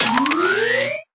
Media:RA_Jessie_Evo.wav UI音效 RA 在角色详情页面点击初级、经典和高手形态选项卡触发的音效